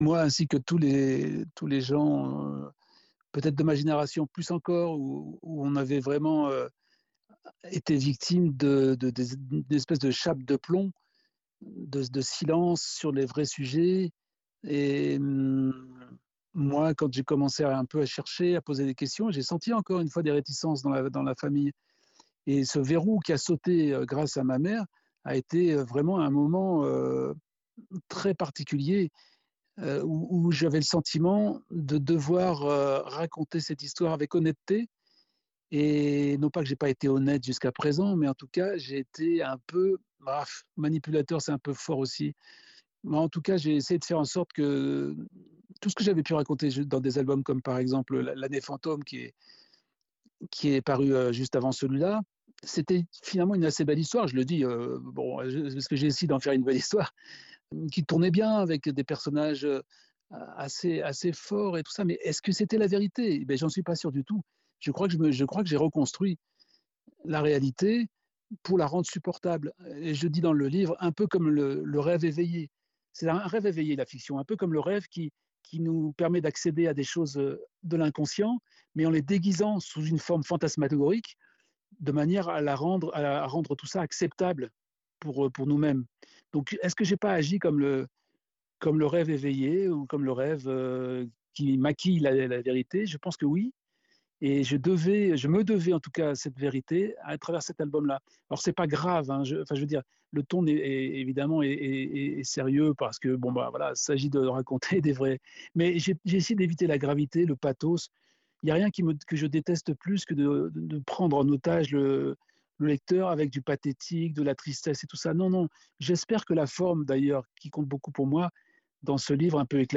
Entretien avec Didier Tronchet